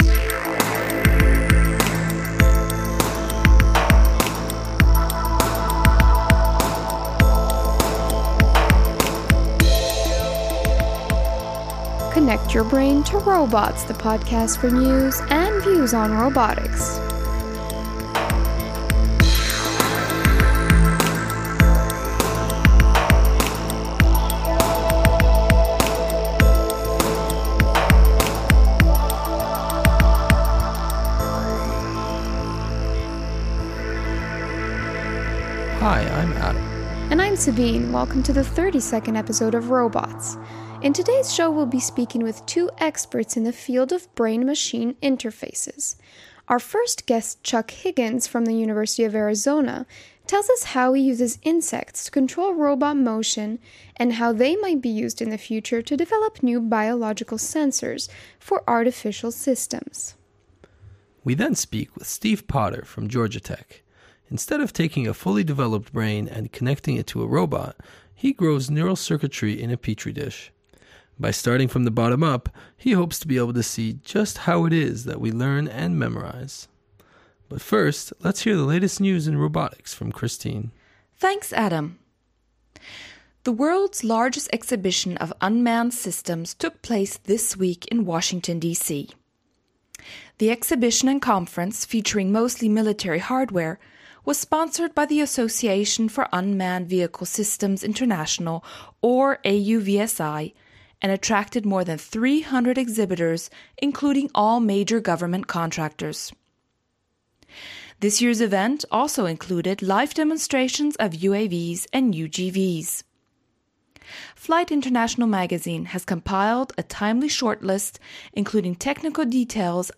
In today’s show we’ll be speaking with two experts in the field of brain-machine interfaces.